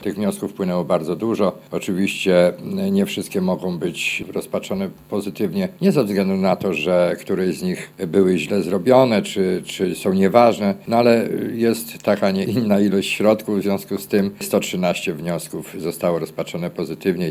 Mówi przewodniczący sejmiku, Jerzy Borcz